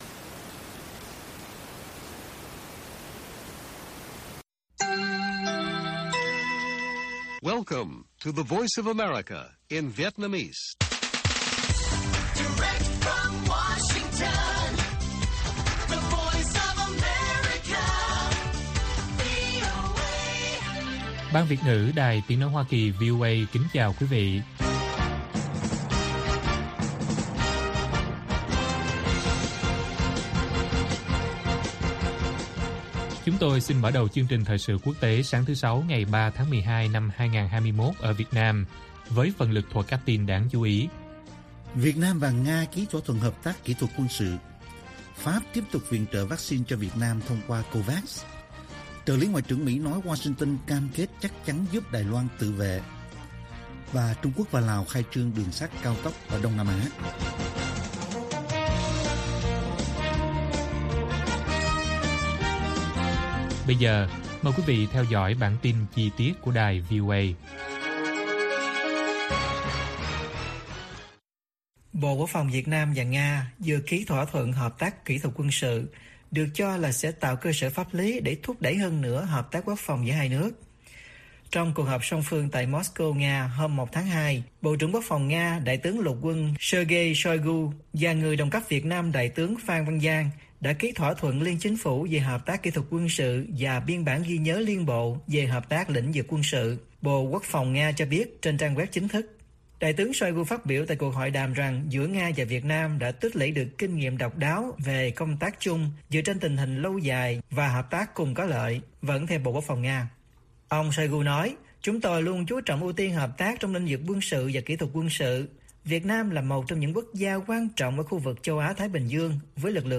Bản tin VOA ngày 3/12/2021